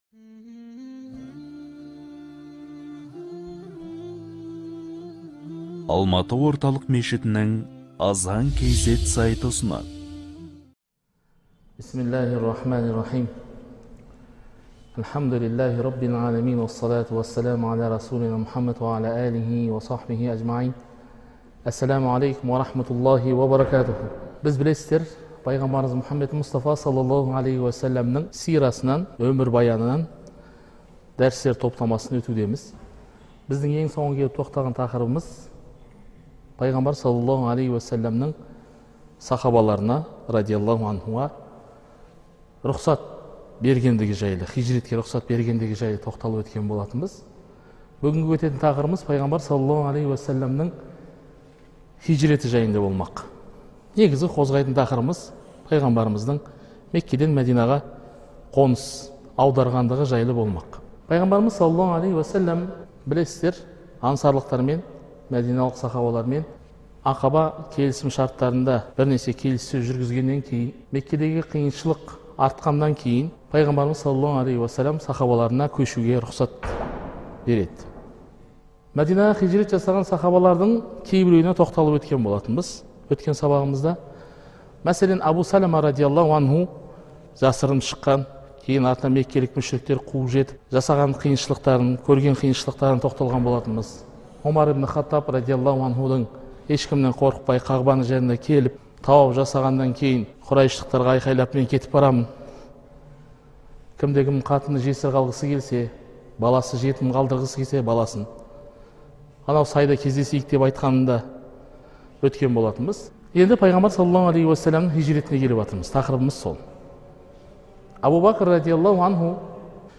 Дәрістер топтамасы